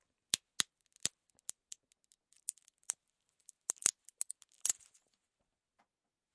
教室 " 笔
描述：钢笔